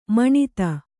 ♪ maṇita